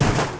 WRECK01.WAV